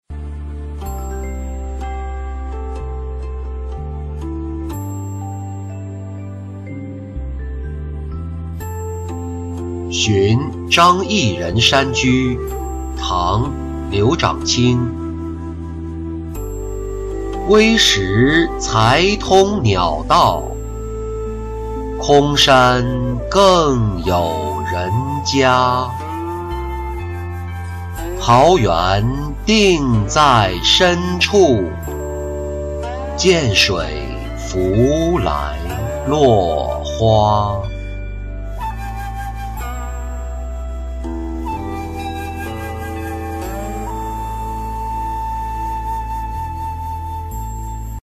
寻张逸人山居-音频朗读